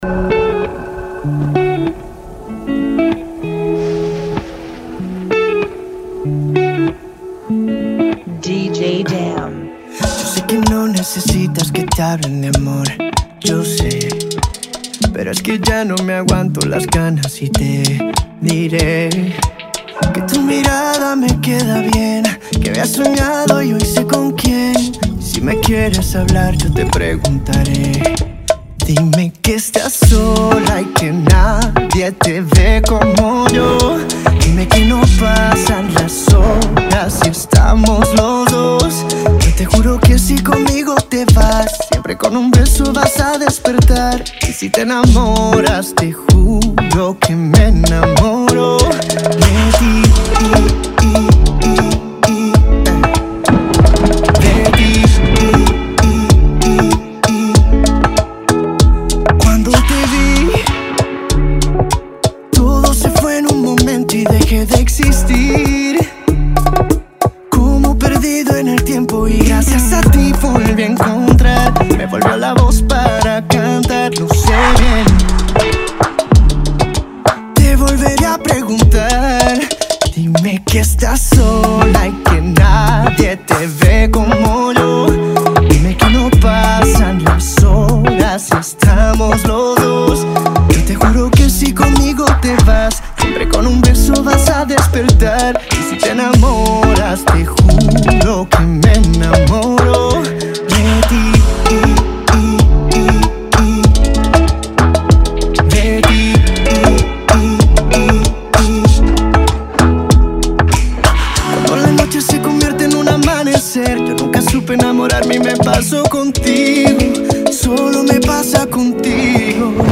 95 BPM
Genre: Bachata Remix